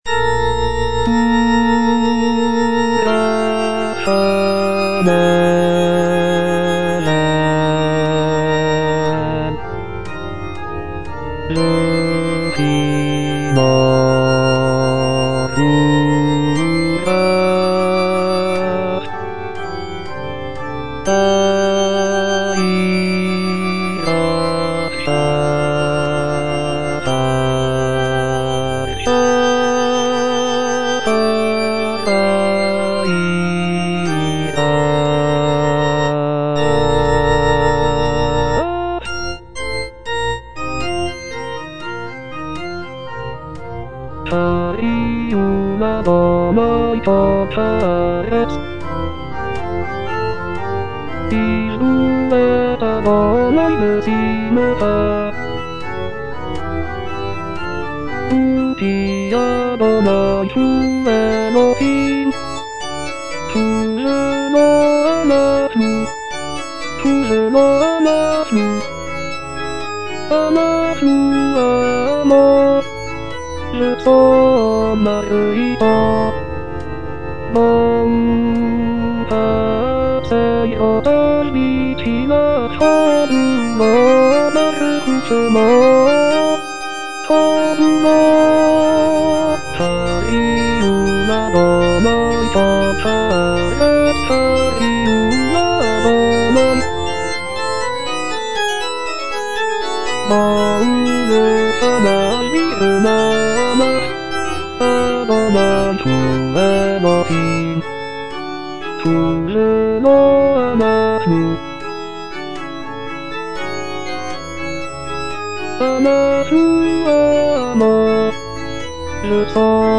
bass I) (Voice with metronome